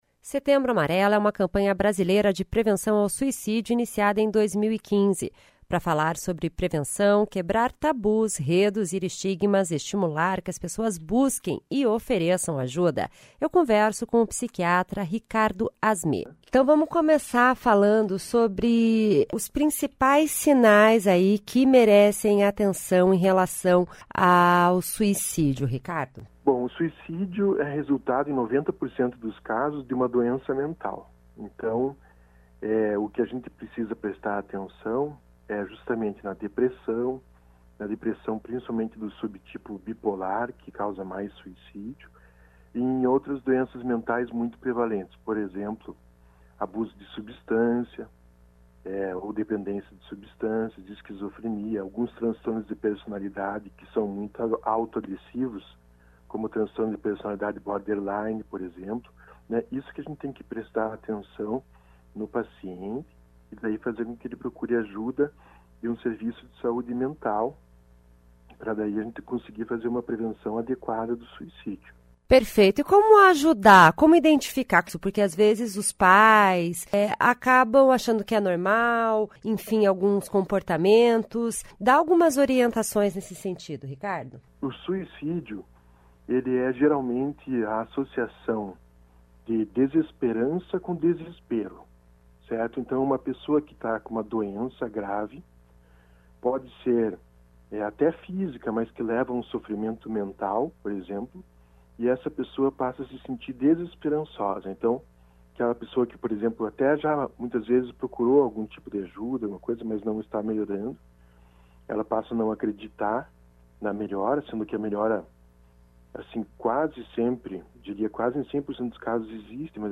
conversa com o psiquiatra